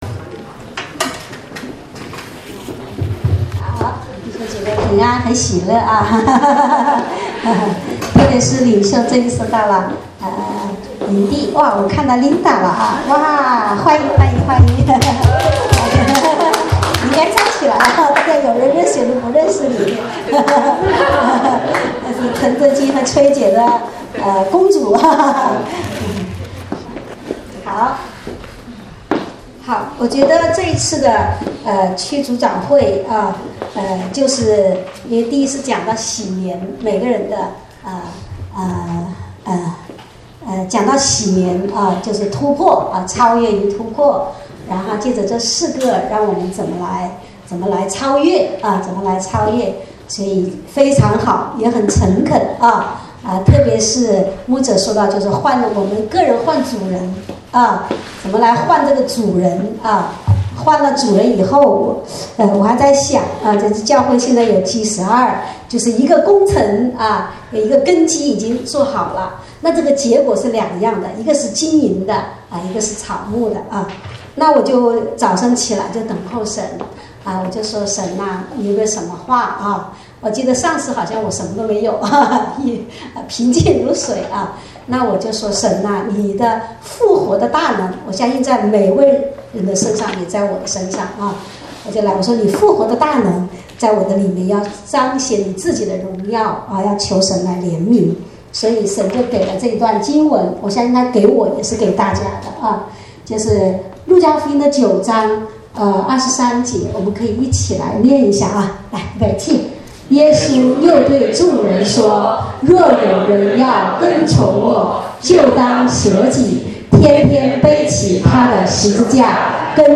主日恩膏聚会（2016-04-24）